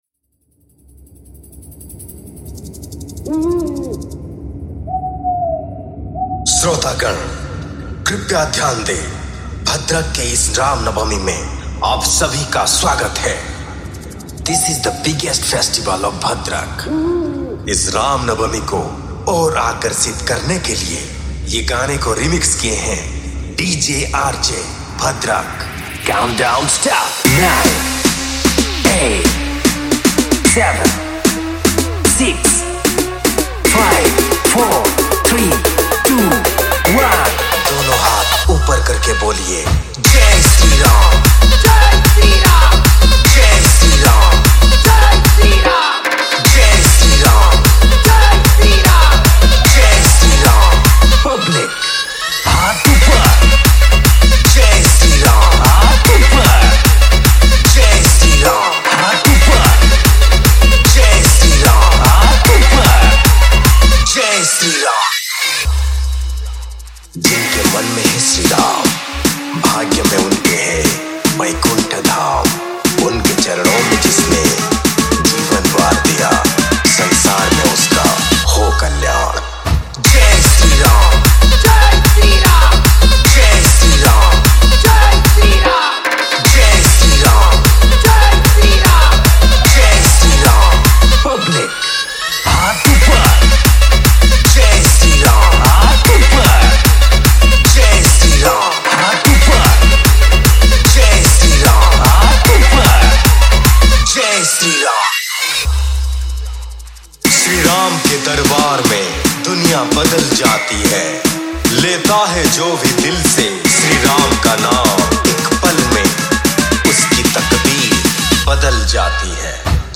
Ram Navami Special Dj Songs Download